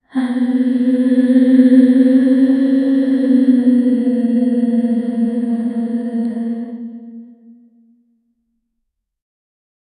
Acrobatics Sound Effects - Free AI Generator & Downloads